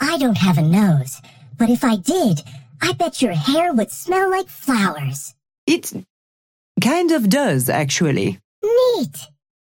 Lady Geist and Viscous conversation 3